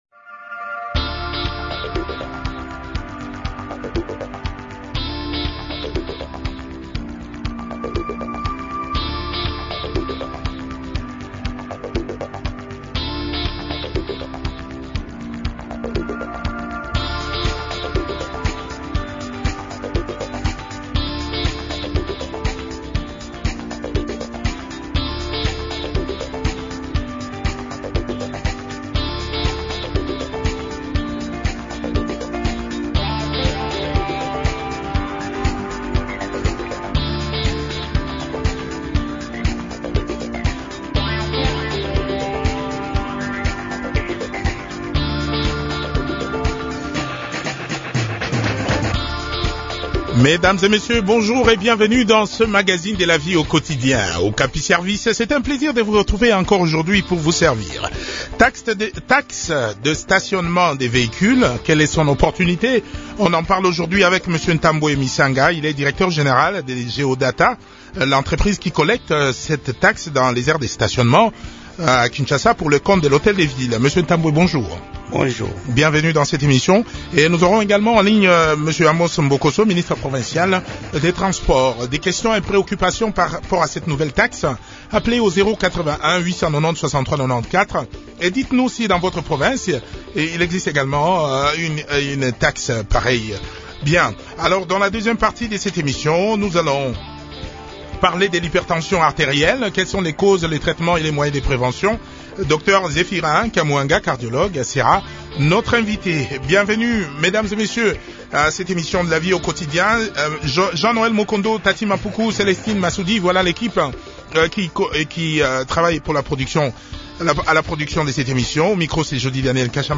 Amos Mbokoso, ministre provincial du transport a aussi participé à cette interview.